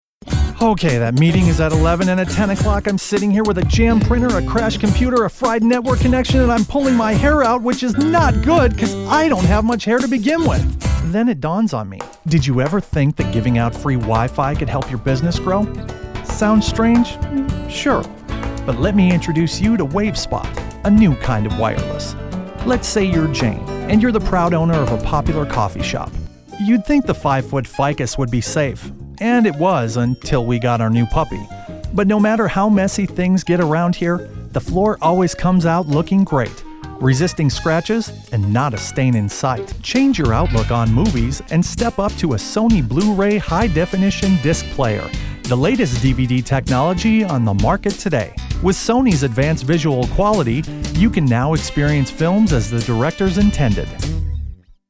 Studio equipment includes a Rode NT1-A condenser microphone and Pro Tools software.
Kein Dialekt
Sprechprobe: Sonstiges (Muttersprache):